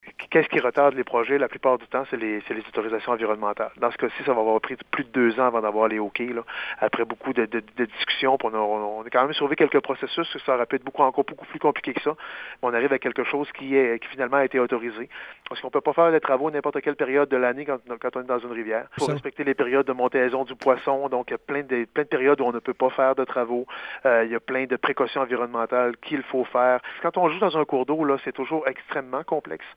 Le maire Daniel Côté explique les raisons entourant tout le processus pour obtenir les approbations de Québec: